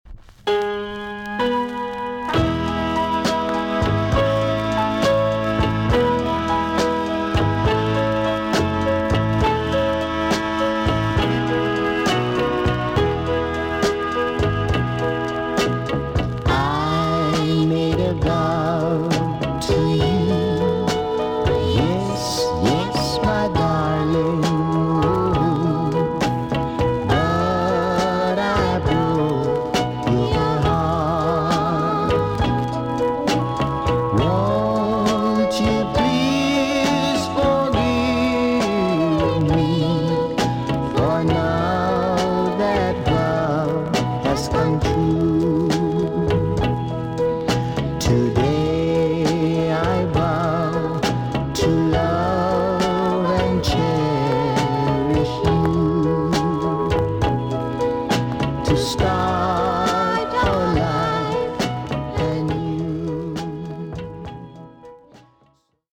TOP >SKA & ROCKSTEADY
VG+ 少し軽いチリノイズが入ります。
UK , NICE BALLAD TUNE!!